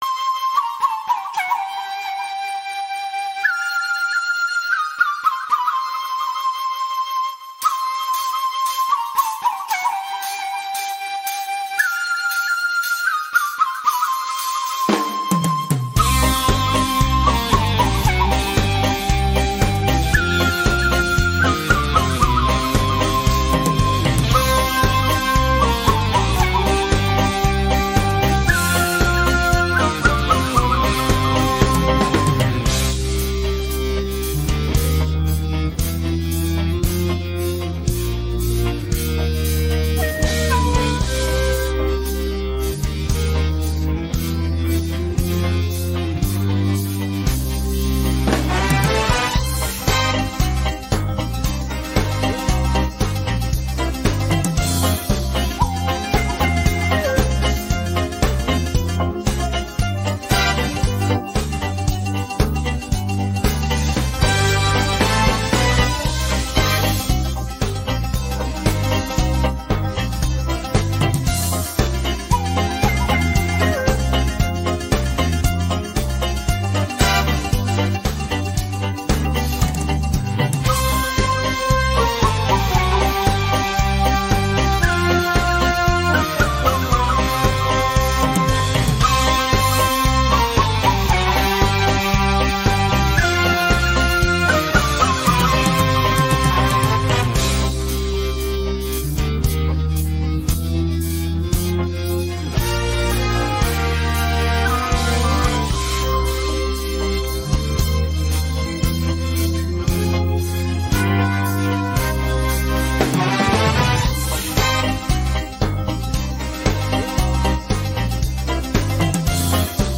pop караоке